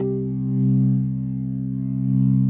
B3LESLIE C 3.wav